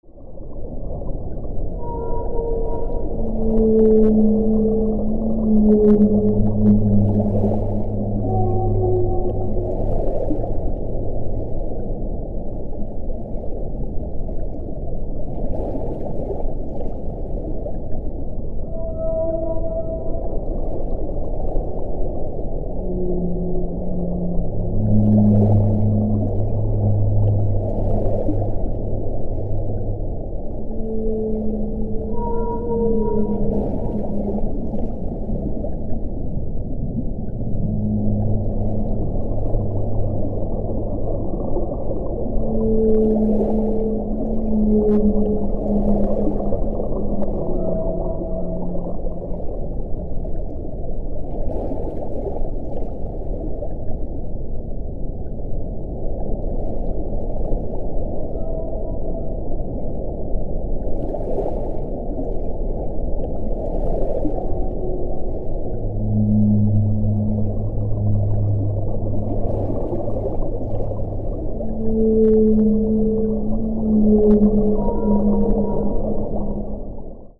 Tiếng Cá Voi kêu MP3